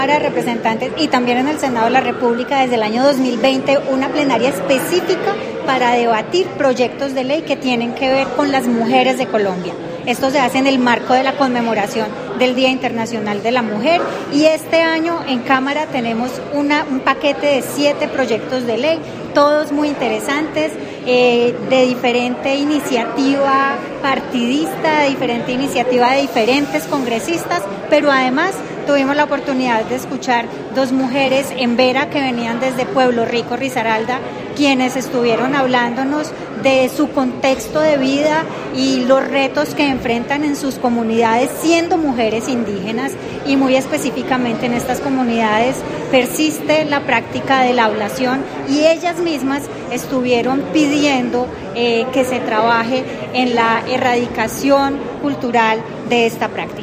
Este miércoles se realiza en la Cámara de Representantes la Plenaria M para debatir Proyectos de Ley que tienen que ver con las mujeres en Colombia. El salón Elíptico fue escenario para que dos mujeres Embera hablaran de la necesidad de a acabar con práctica de ablación en sus comunidades.